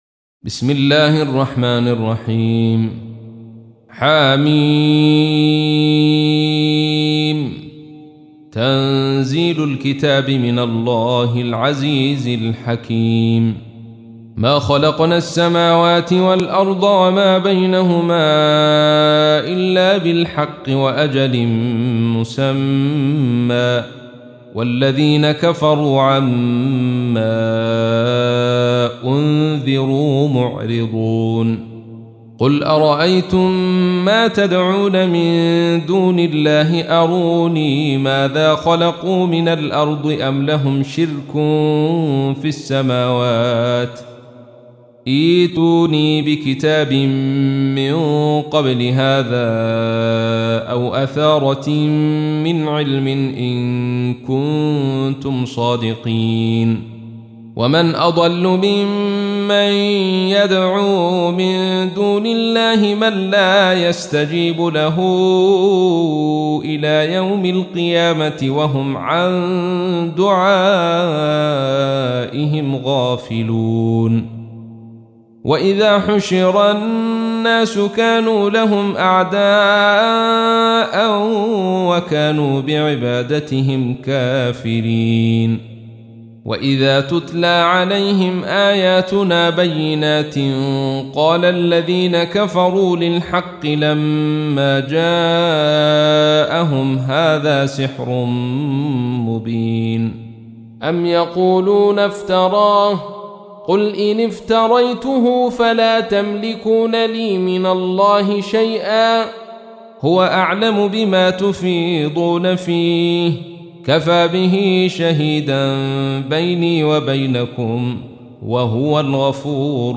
تحميل : 46. سورة الأحقاف / القارئ عبد الرشيد صوفي / القرآن الكريم / موقع يا حسين